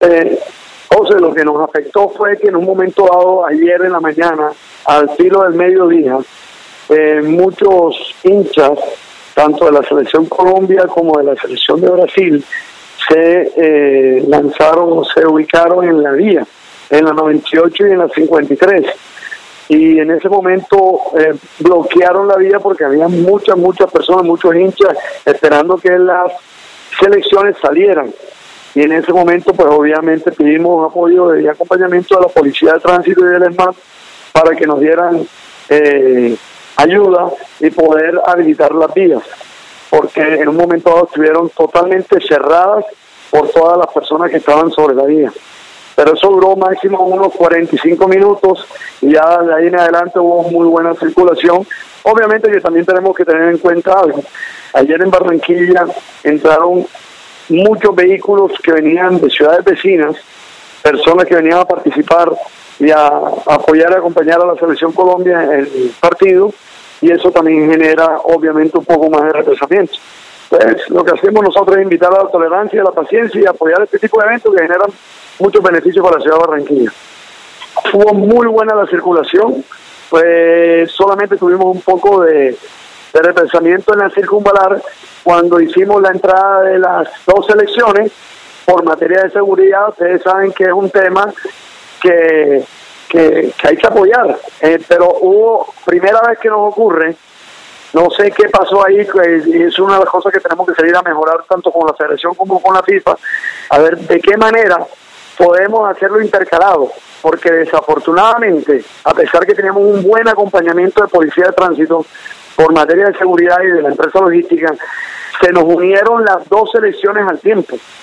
«Por eso en próximos partidos de la Selección en Barranquilla, se le solicitará a la Federación o a la Fifa que estudien la posibilidad que los buses salgan con un intervalo de tiempo prudencial», concluyó Isaza en diálogo con Atlántico en Noticias…